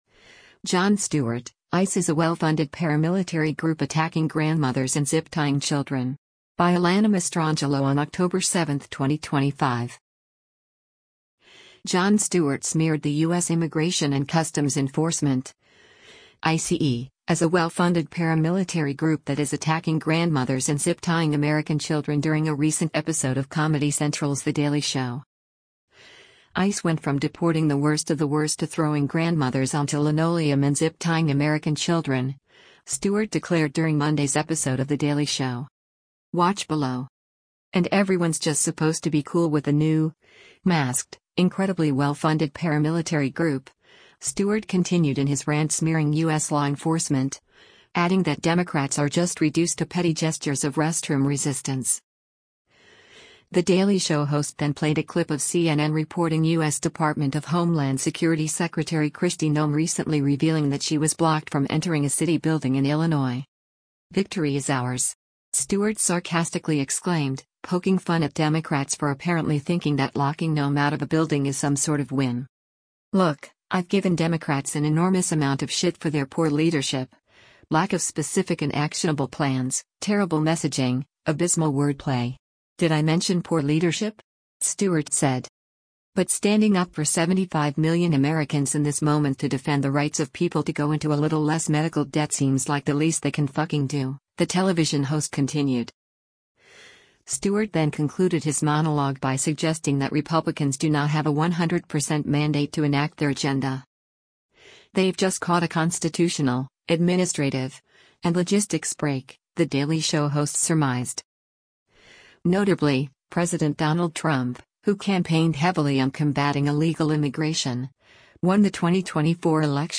The Daily Show host then played a clip of CNN reporting U.S. Department of Homeland Security Secretary Kristi Noem recently revealing “that she was blocked from entering a city building in Illinois.”
“Victory is ours!” Stewart sarcastically exclaimed, poking fun at Democrats for apparently thinking that locking Noem out of a building is some sort of win.